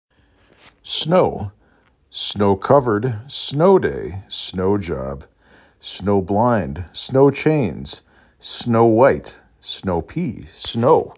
4 Letters, 1 Syllable
3 Phonemes
s n O